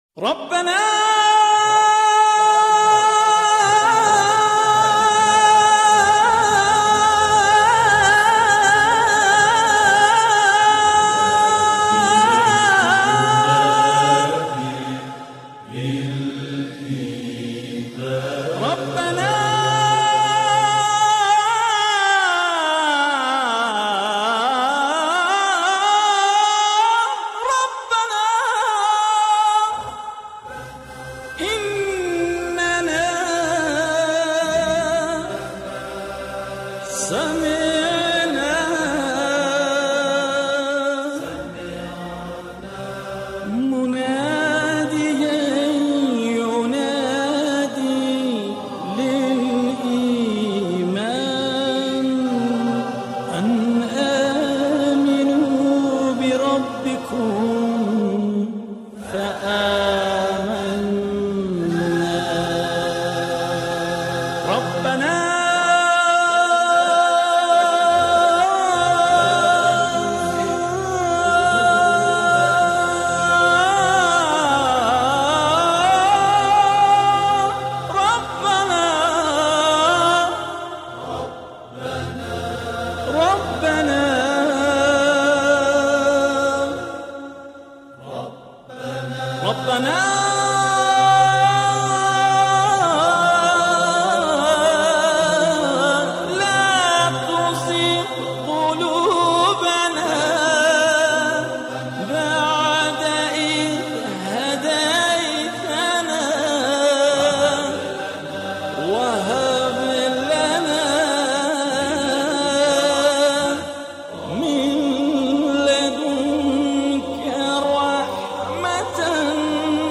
آکاپلا